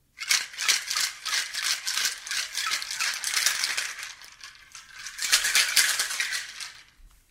Tiếng Bartender lắc đá, đồ uống, bình lắc kim loại, shaker…
Thể loại: Tiếng ăn uống
Description: Tiếng bartender lắc đá trong bình lắc kim loại (shaker) pha chế cocktail. Âm thanh này, kết hợp tiếng đá va chạm, mang đến cảm giác tươi mới, chuyên nghiệp, lý tưởng cho video, làm nổi bật cảnh pha chế đồ uống đầy tinh tế.
tieng-bartender-lac-da-do-uong-binh-lac-kim-loai-shaker-www_tiengdong_com.mp3